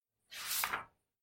纸张 " 用人手握住纸张
描述：单声道声音wav 24纸干。